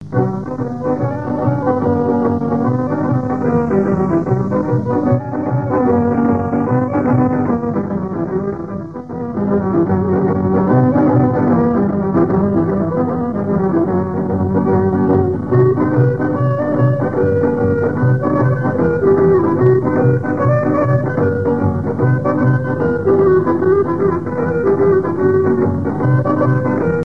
danse : horo (Bulgarie)
Pièce musicale inédite